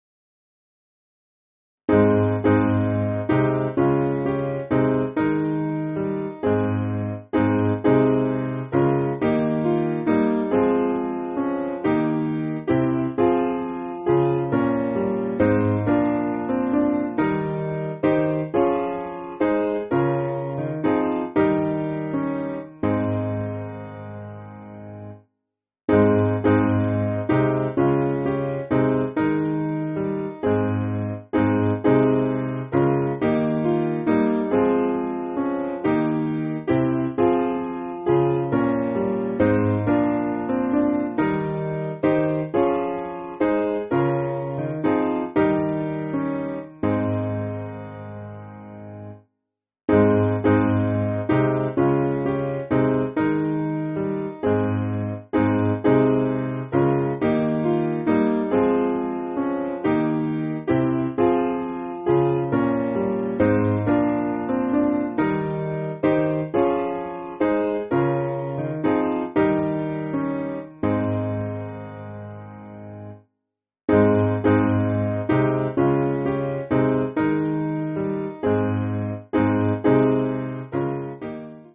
Simple Piano
(CM)   4/Ab